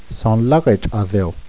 Soohn lah ghe tch ah theyl